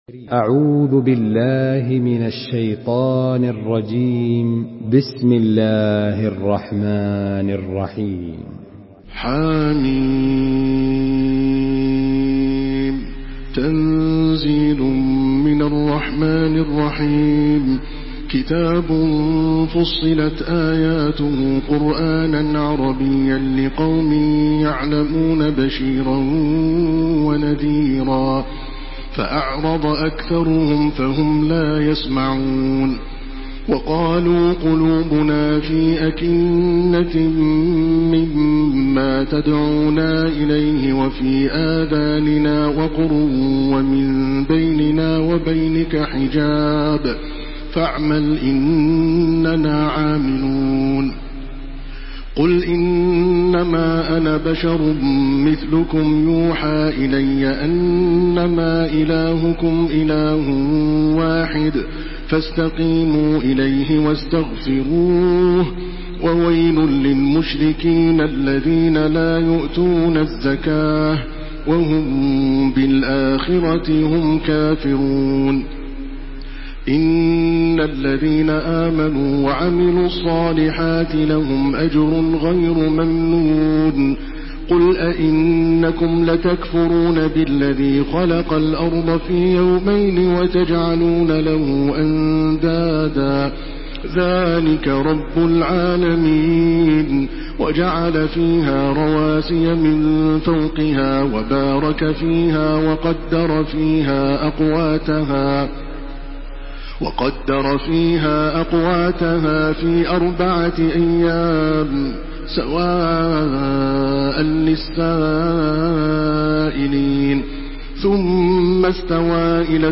Download Surah Fussilat by Makkah Taraweeh 1429
Murattal